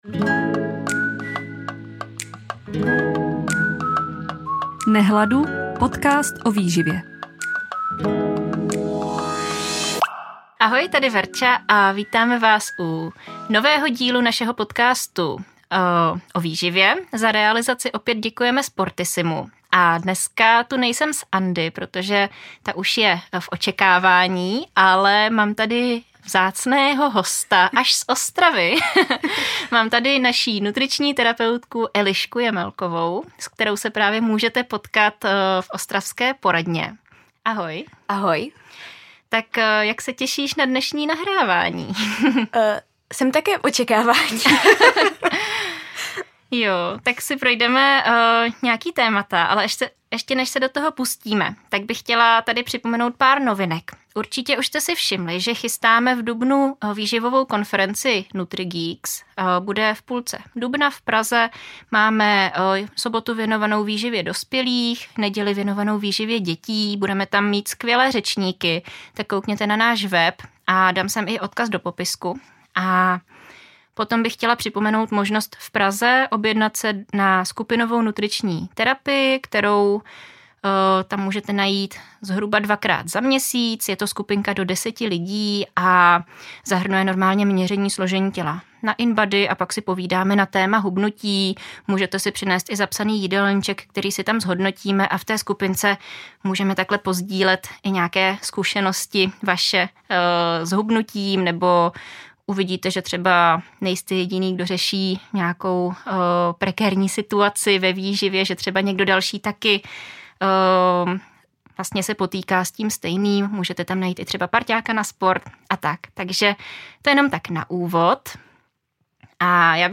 Tento díl zahajuje sérii rozhovorů s našimi nutričními terapeutkami.